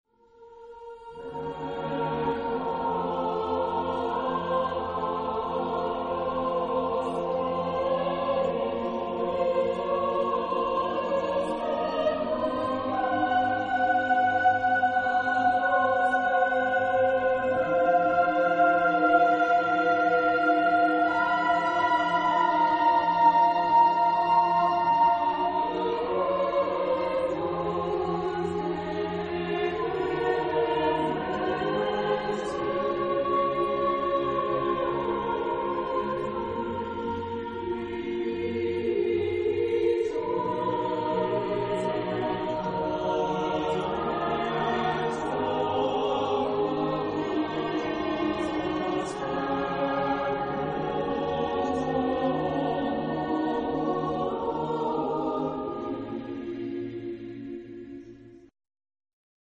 Type of Choir: SATB  (4 mixed voices )
Instruments: Organ (1) or Piano (1)